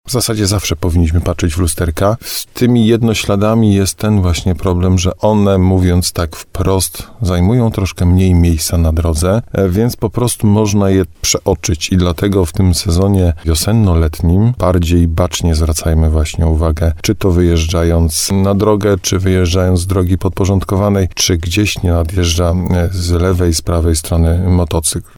– Część kierowców samochodów zdążyła przez ostatnie miesiące zapomnieć o jednośladach – mówił w programie Słowo za Słowo na antenie RDN Nowy Sącz